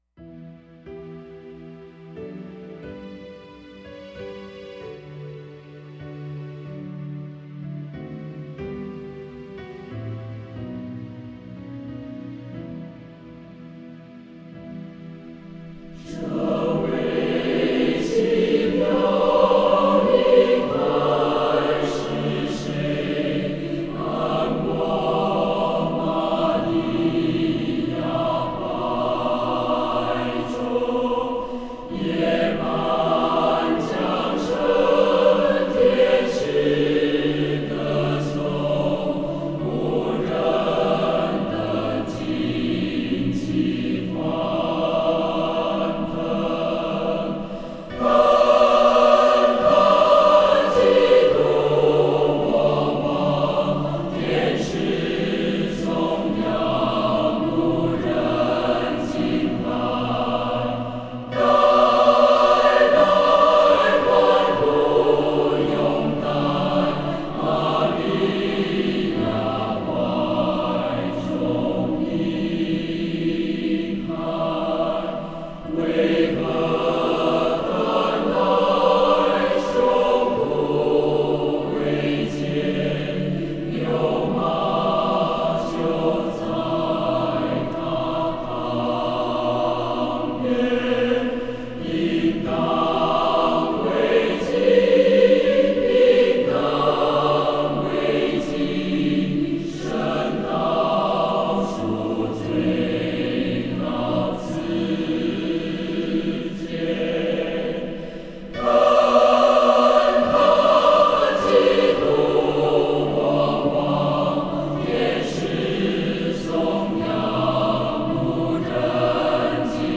曲：十六世纪英国曲调